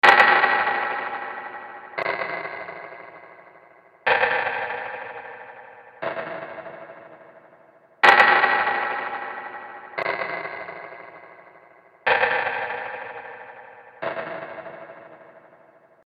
J’aime beaucoup triturer des sons sur ma fender telecoustic (c’est une guitare electroacoustique assez moyenne mais qui sort des choses assez étranges et originales quand on n’en fait pas l’usage pour lequel elle a été fabriquée).
J’ai donc branché cette guitare en direct dans ma pédale chérie, la Holier Grain d’Electro Harmonix, j’ai lancé l’enregistrement et j’ai joué, j’ai aussi fait tomber la guitare, j’ai tapé sur la table d’harmonie avec mes mains, j’ai frappé les cordes avec des baguettes de batterie.
Une fois que j’ai fini de m’amuser, j’ai samplé ce qui me semblait être le meilleur passage, un petit bout de quelques secondes qui donne ceci :